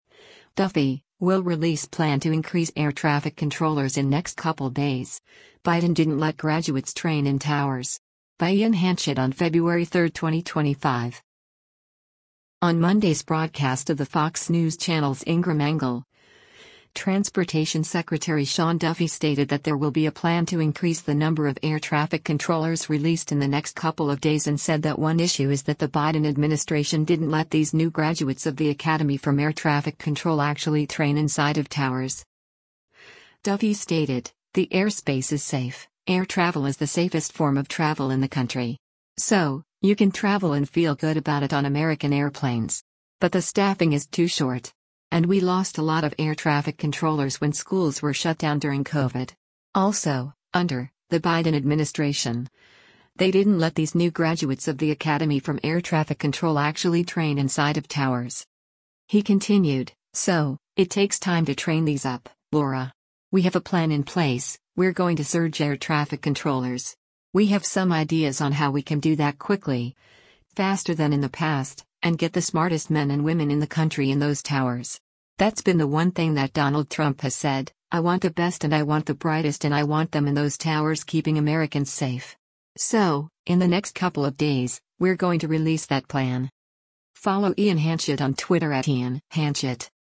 On Monday’s broadcast of the Fox News Channel’s “Ingraham Angle,” Transportation Secretary Sean Duffy stated that there will be a plan to increase the number of air traffic controllers released “in the next couple of days” and said that one issue is that the Biden administration “didn’t let these new graduates of the academy from air traffic control actually train inside of towers.”